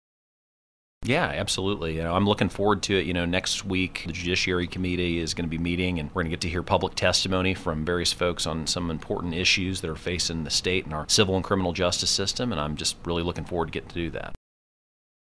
4. Senator Luetkemeyer says the first committee hearing on his priority legislation also comes next.